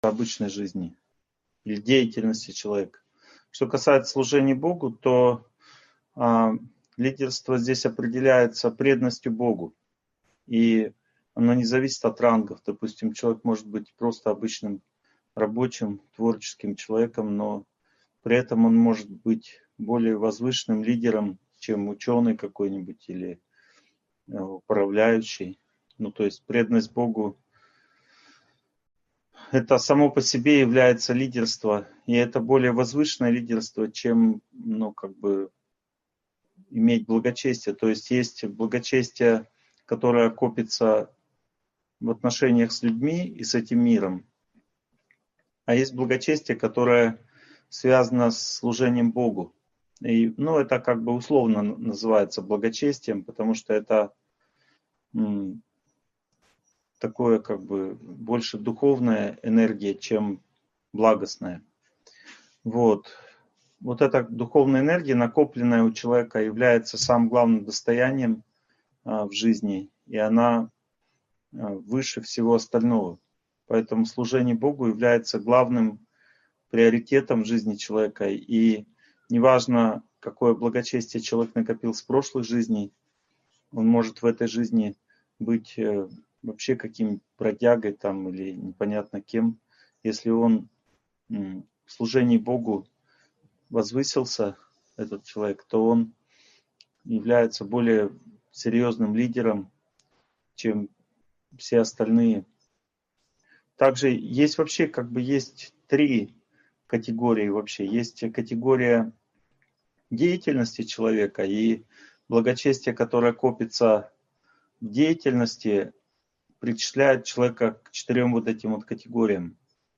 Лидеры в благости, страсти и невежестве (онлайн-семинар, 2021)